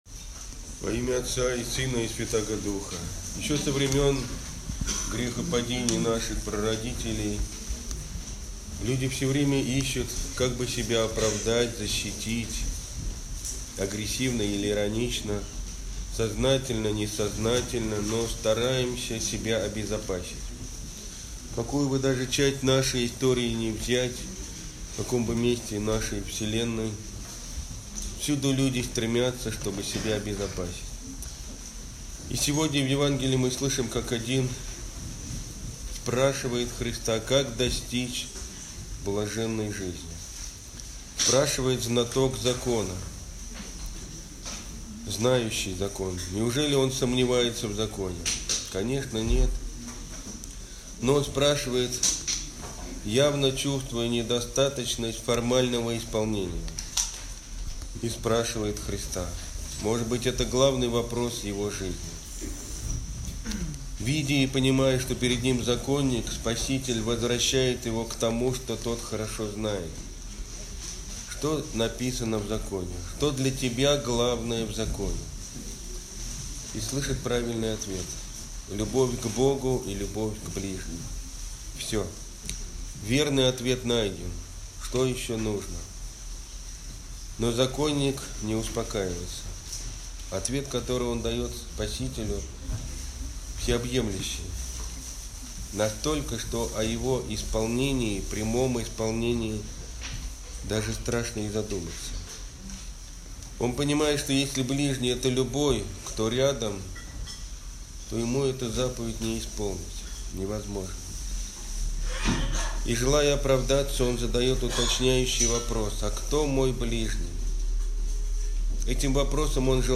Аудиопроповеди